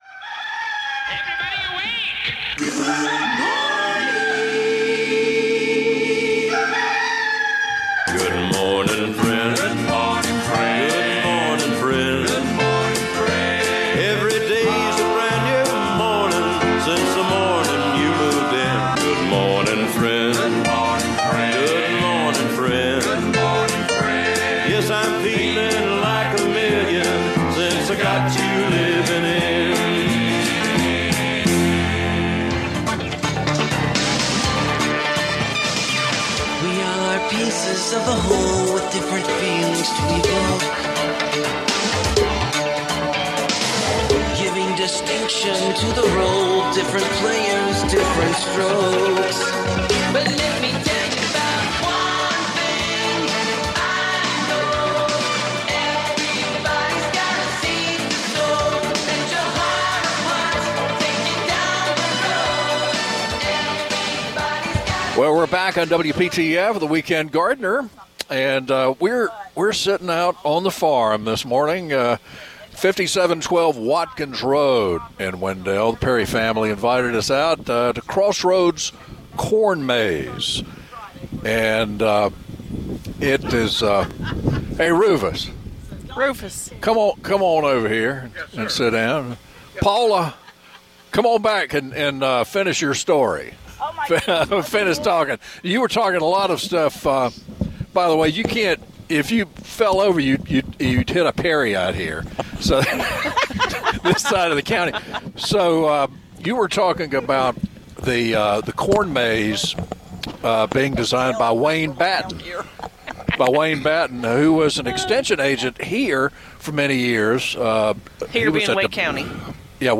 Weekend Gardener - Live from Crossroads Corn Maze, Wendell (Part 2 Of 3)